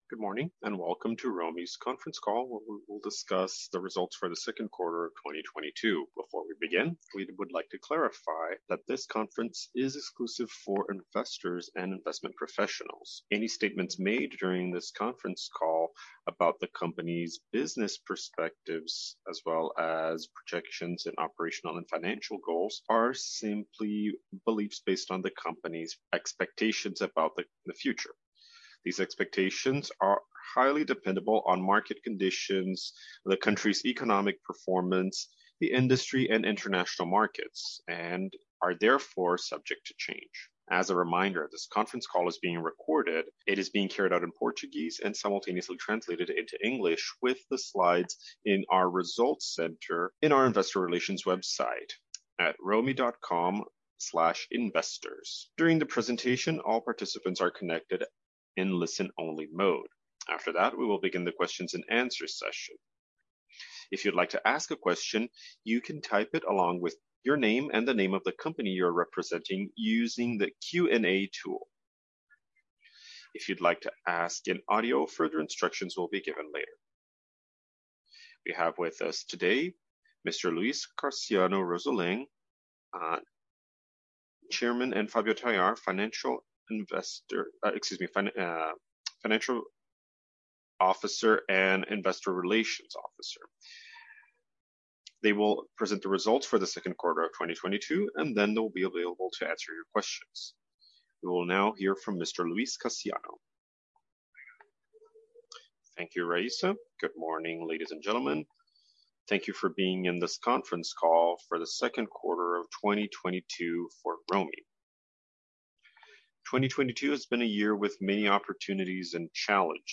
Audio from Quarter Teleconference